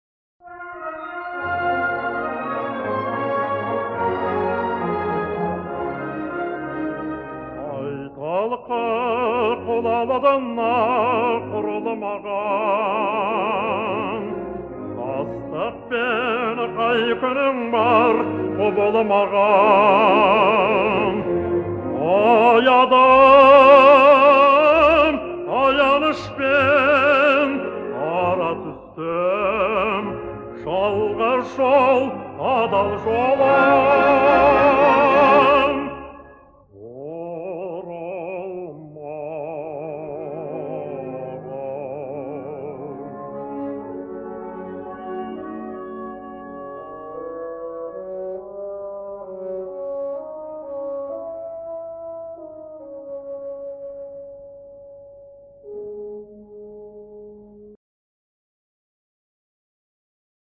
ПЕСНИ И АРИИ